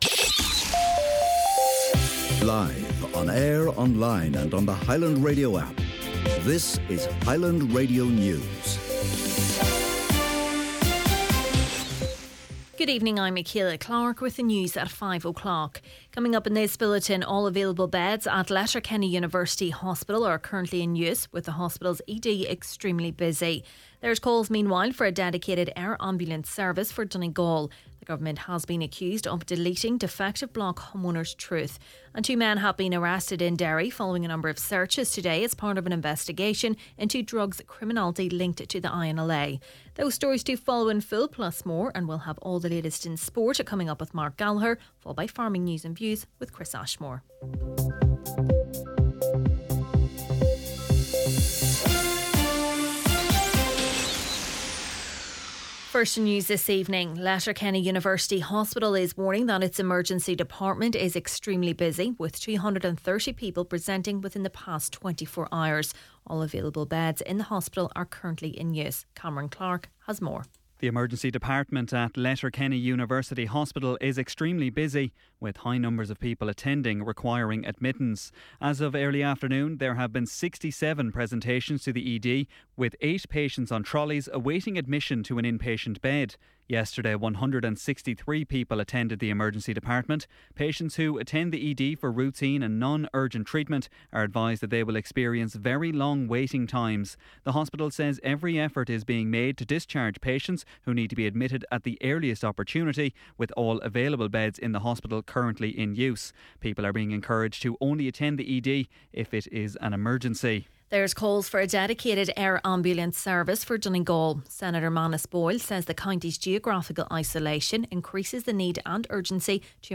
Main Evening News, Sport, Farming News and Obituaries – Thursday, October 16th